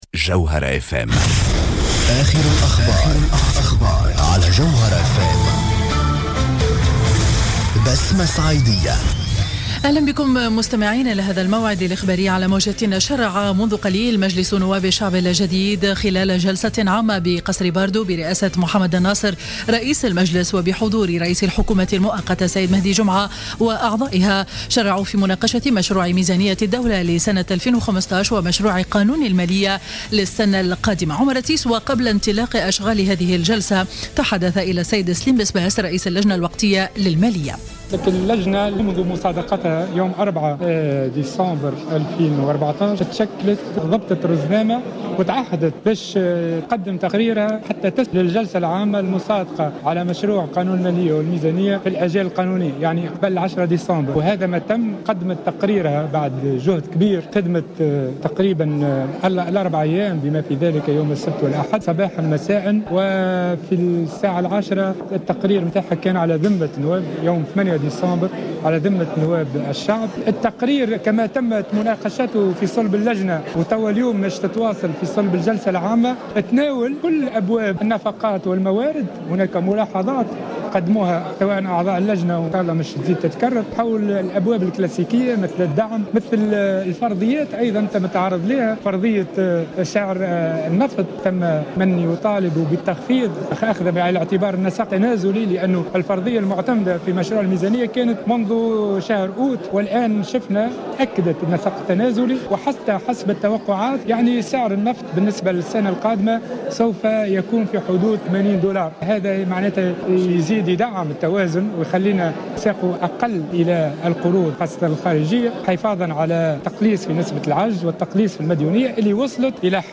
نشرة أخبار منتصف النهار ليوم الاربعاء 10-12-14